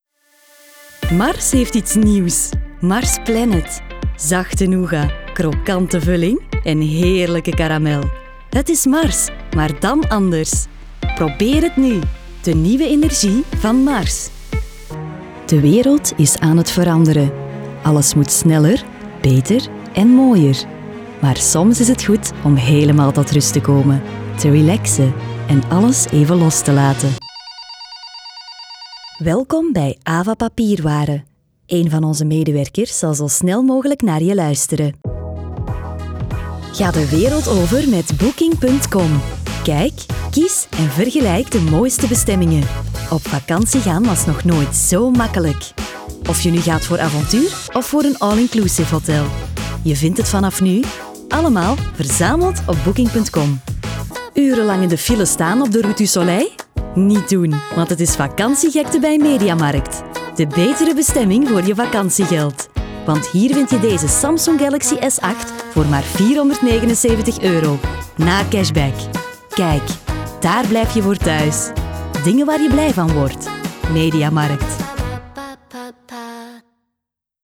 Professionele voice-over, sprekend voor jouw verhaal.
Homestudio.
Een stem die warmte en vertrouwen uitstraalt.
Een toegankelijke toon die meteen aanspreekt.
Mijn stem past zich moeiteloos aan diverse stijlen en genres aan.